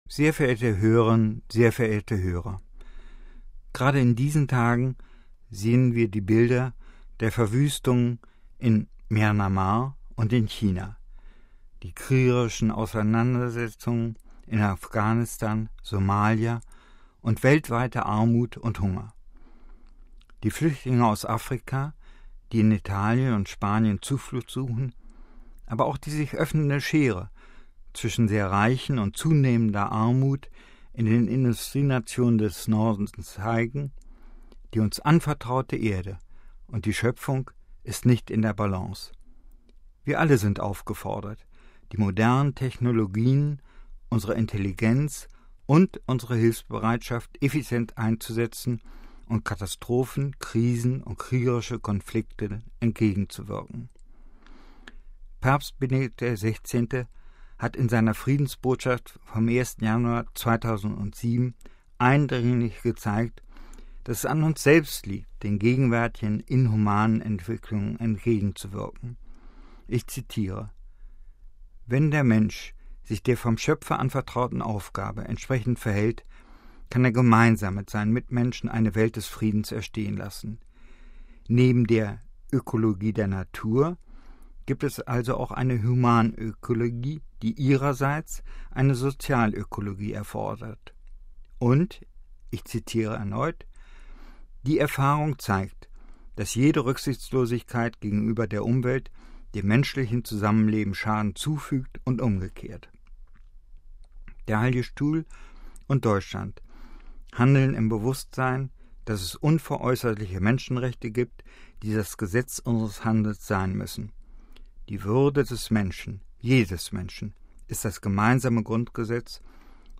MP3 Einmal im Monat bitten wir den Botschafter Deutschlands beim Vatikan, Dr. Hans-Henning Horstmann, um einen Kommentar zum Weltgeschehen - mit Blick auf den Vatikan.